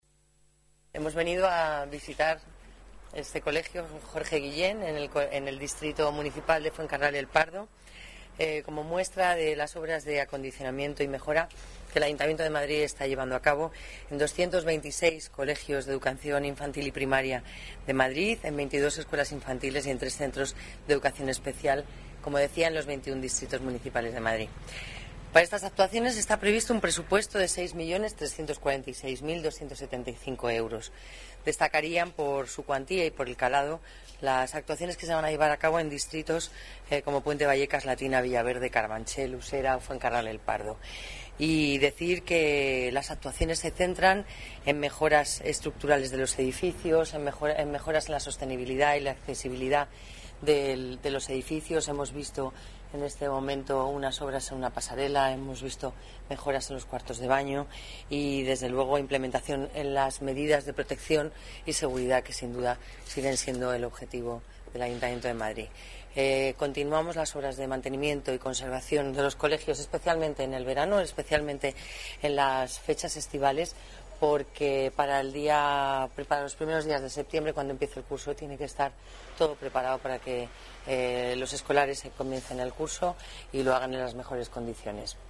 Nueva ventana:Declaraciones delegada de Familia y Servicios Sociales, Lola Navarro: visita colegio Jorge Guillén, renovación centros escolares